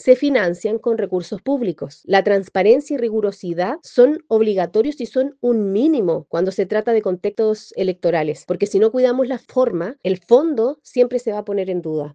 Desde el Congreso, la diputada del Partido Humanista por el distrito de Tarapacá, Mónica Arce, advirtió que si no se cuida la forma, el fondo siempre quedará en duda.